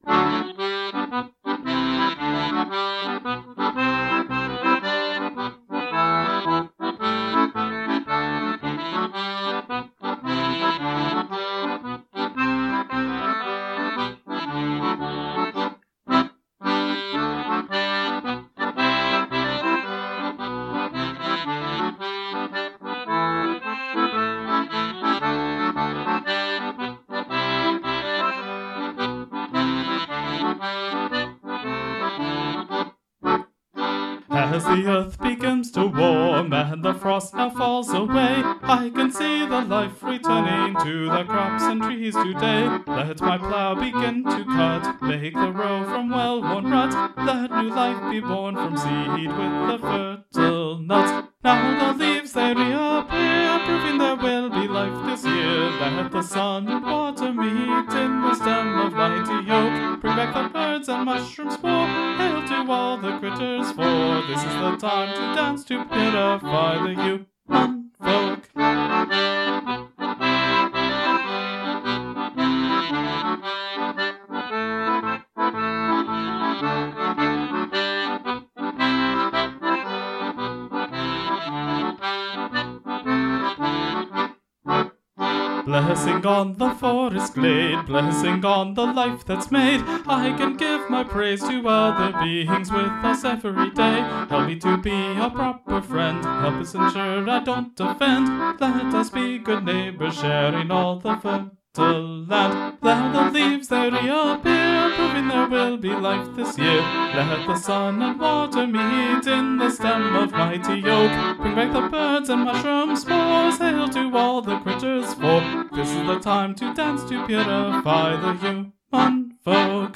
The rhythm of this is a standard jig, common to Irish music. This beat is often used for dancing, because it has a combination of the flowing feel of groups of 3 notes, combined with the easy walking of 2 larger beats.
My apologies for the wheezy accordion on the recording - there's a technological problem with the instrument I have not yet had a chance to fix.